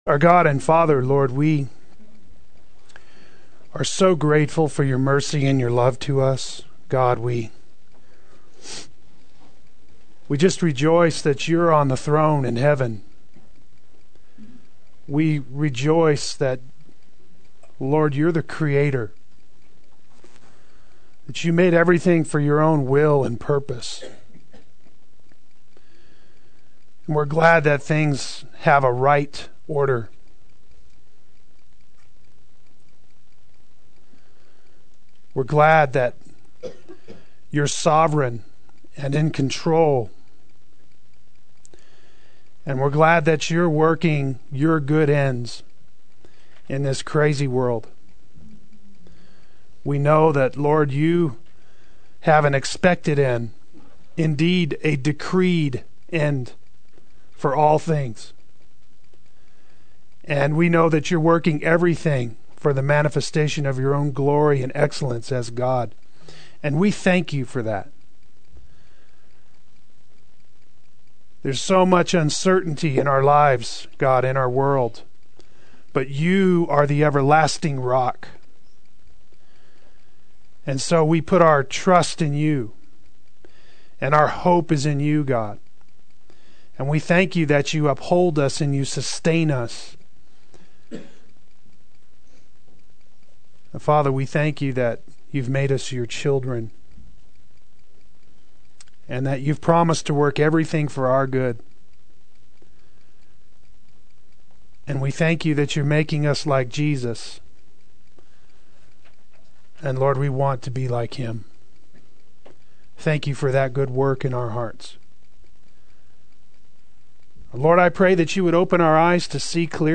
Play Sermon Get HCF Teaching Automatically.
and Wail Adult Sunday School